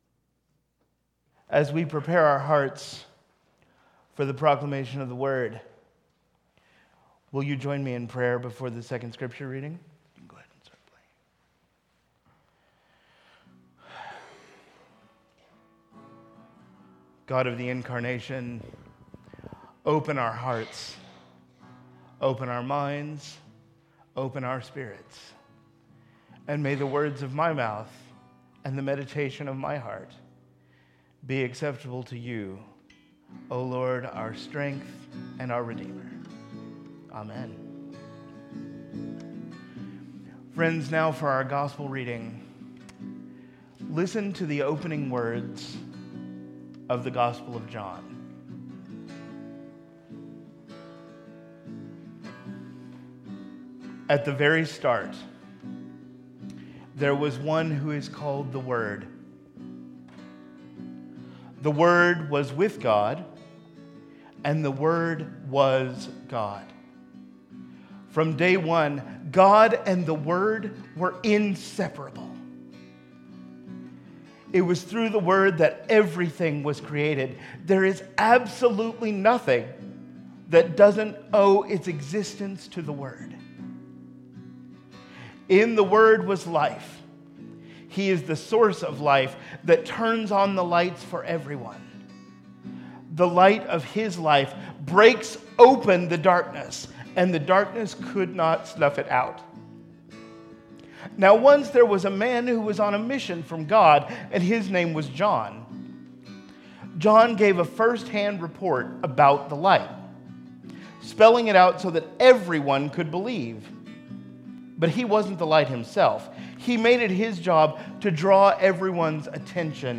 Worship Service